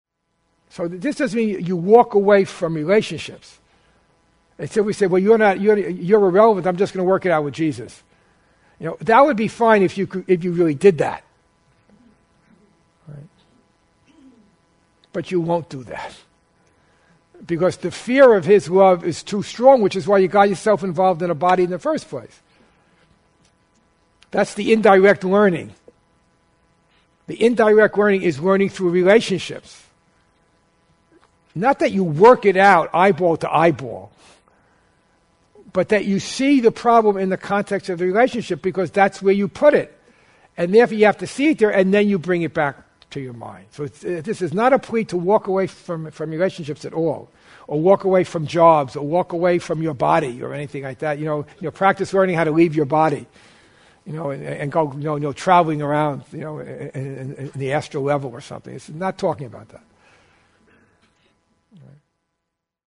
This set combines a seminar (“The Golden Thread of Hope”) and Academy class (“A Transition in Perspective”) from 2010, held at the Foundation in Temecula, California. The common theme in these talks is the transition Jesus asks us to make from body to mind.